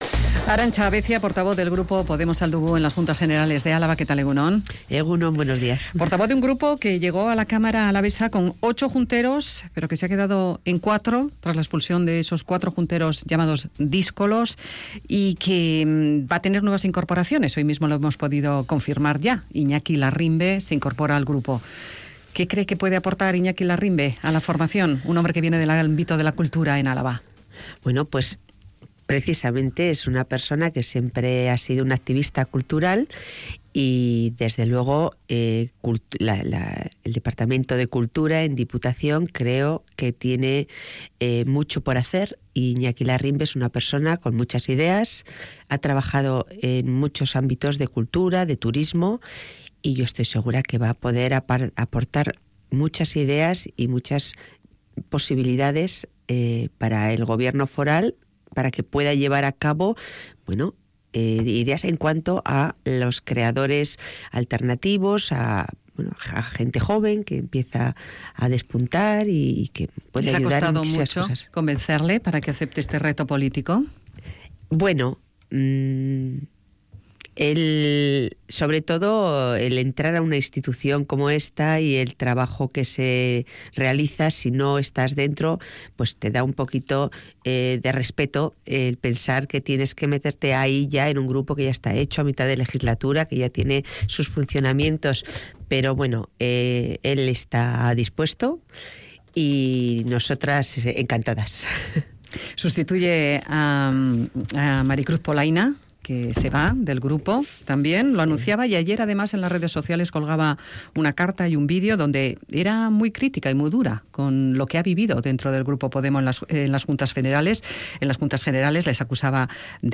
Radio Vitoria ENTREVISTAS